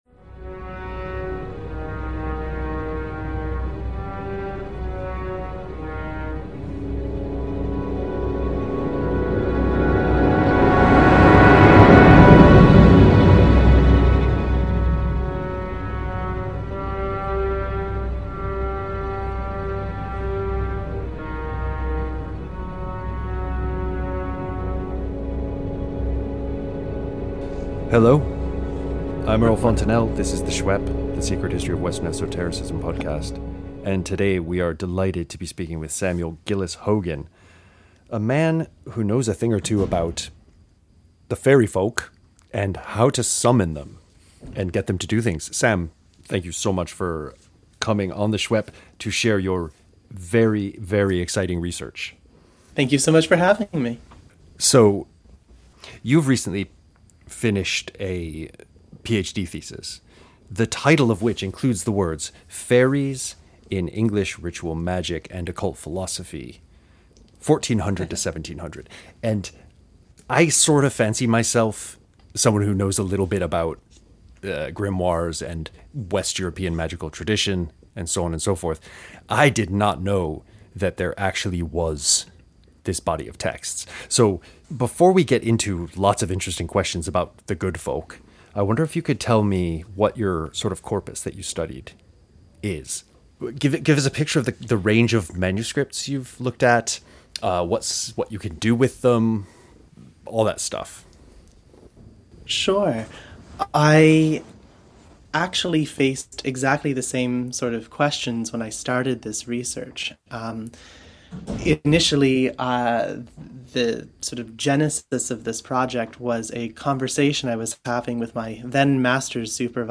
[Sorry for the glitches in the recording; the internet-faeries were especially mischievous that day.
Interview Bio